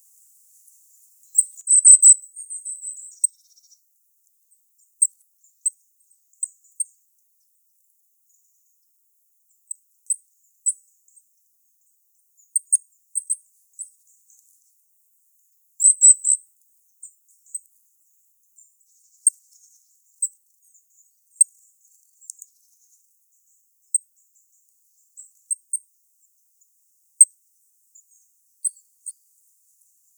Regulus regulus - Goldcrest - Regolo
E 11,2679° - ALTITUDE: 0 m. - VOCALIZATION TYPE: various call types.
It calls repeatedly, using different call types.
Background: Goldcrest calls (see spectrograms).